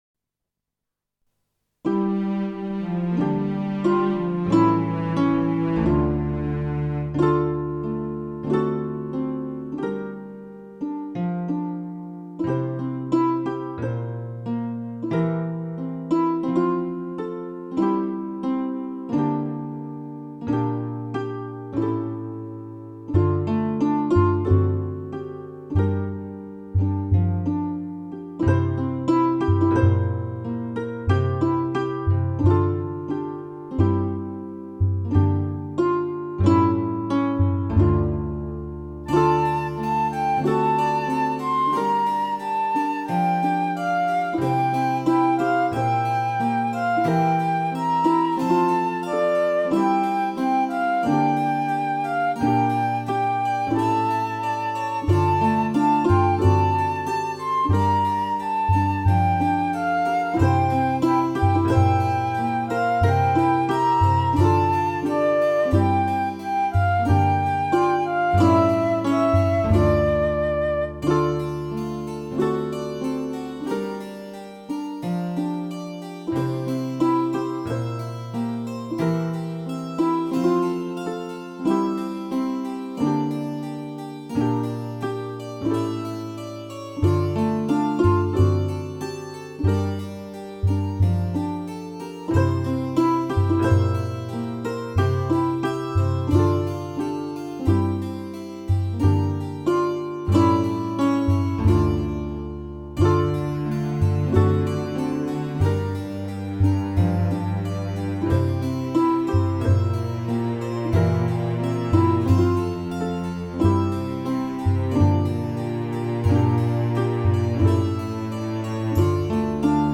bande son